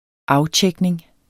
Udtale [ ˈɑwˌtjεgneŋ ]